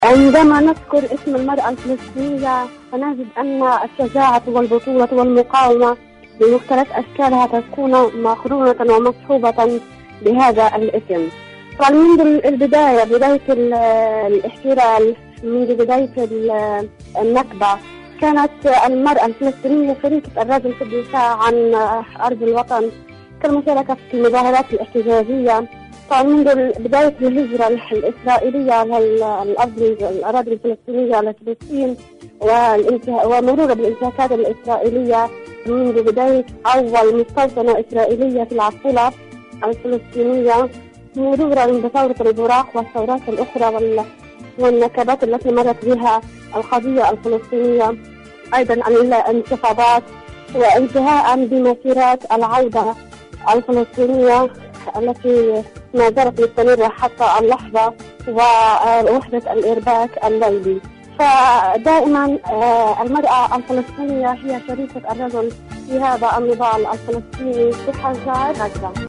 إذاعة طهران-عالم المرأة: